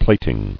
[plat·ing]